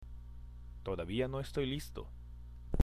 ＜発音と日本語＞
（トダビア　ノ　エストイ　リスト）